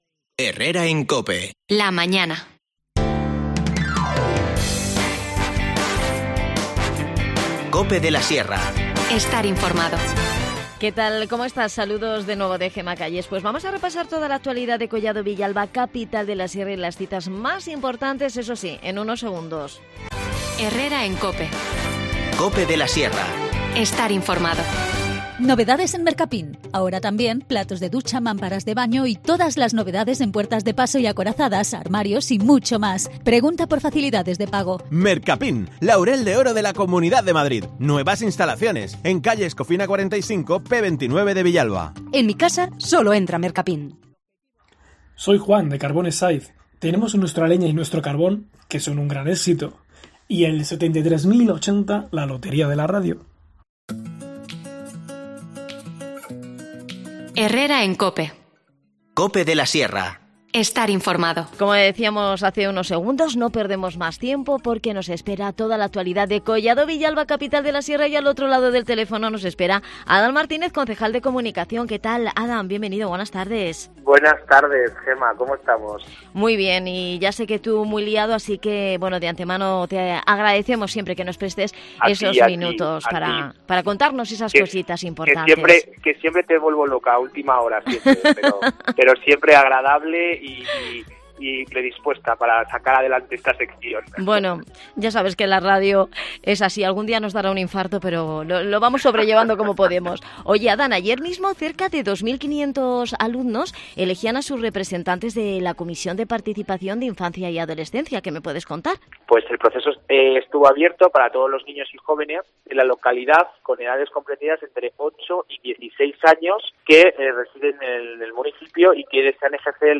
Adan Martínez, concejal de Comunicación en Collado Villalba, Capital de la Sierra, repasa la actualidad del municipio y entre otras cuestiones nos habla del Festival del Chuletón que acogerá este fin de semana La Carpa Malvaloca.